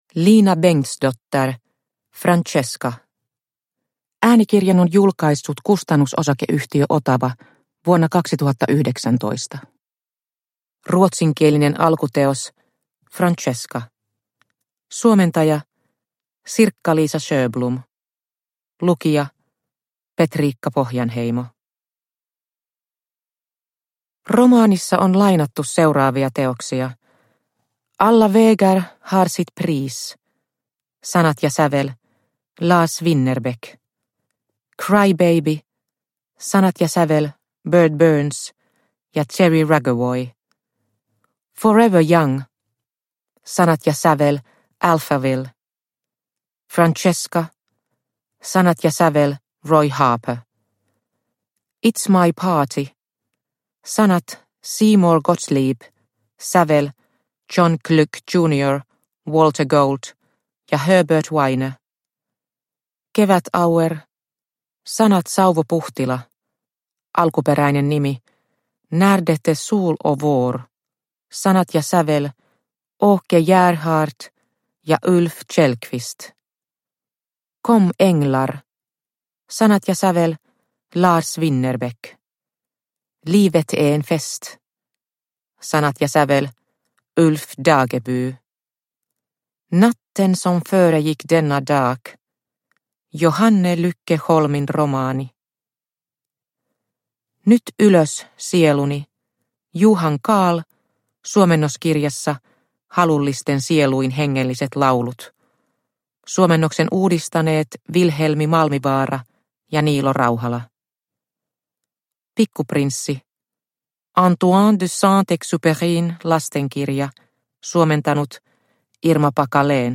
Francesca – Ljudbok – Laddas ner